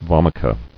[vom·i·ca]